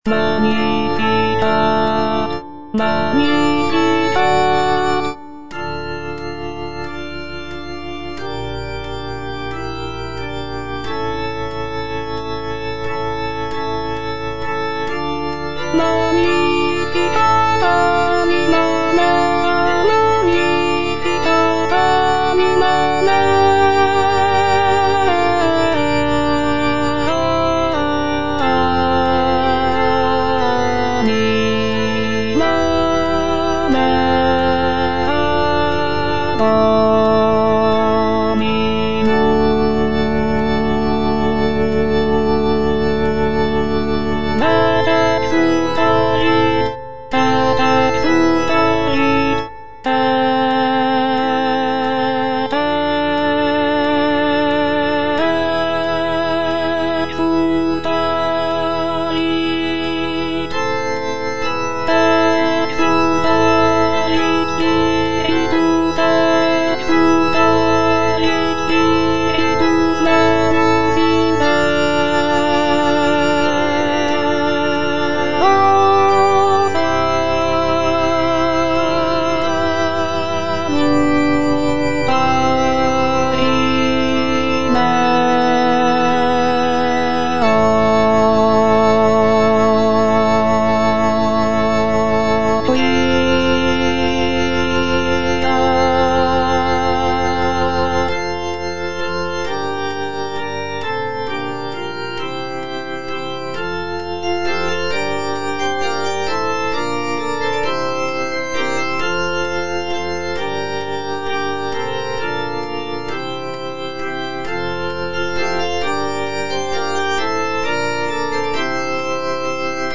C. MONTEVERDI - MAGNIFICAT PRIMO (EDITION 2) Alto II (Voice with metronome) Ads stop: